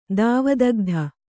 ♪ dāva dagdha